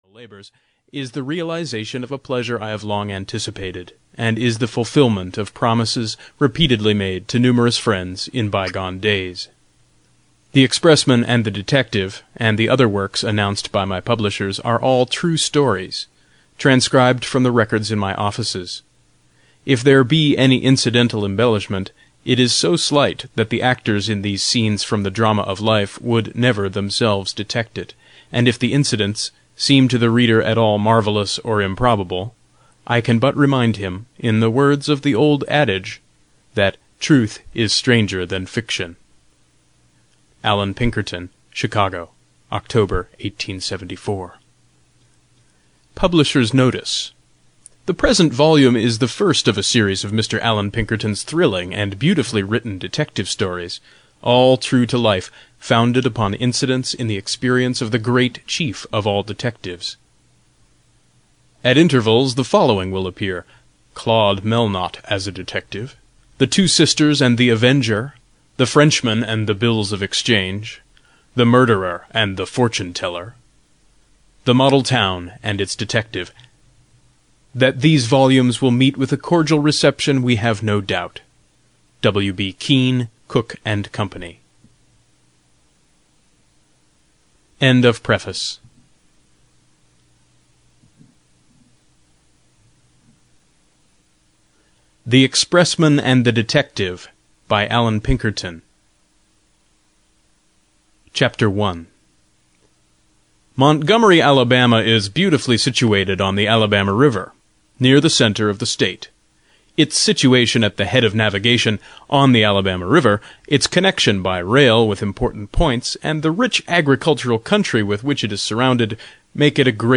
The Expressman and the Detective (EN) audiokniha
Ukázka z knihy